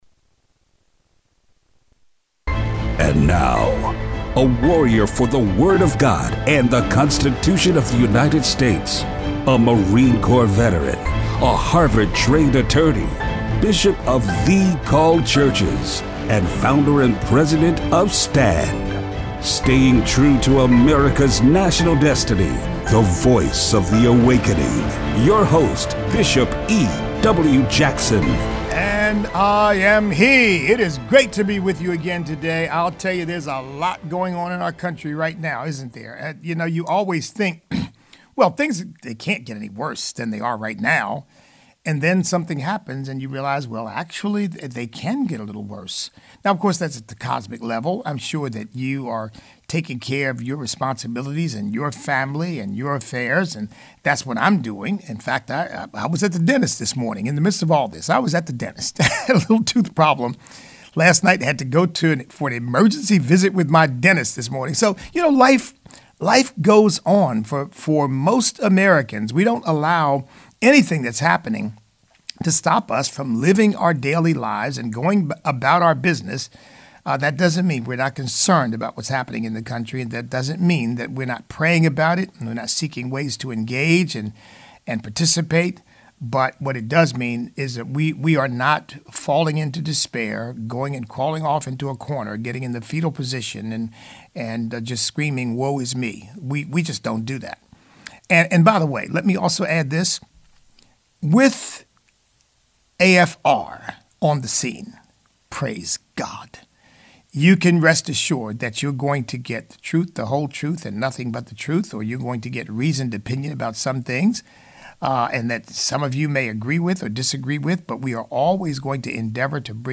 Show Notes Open phone lines.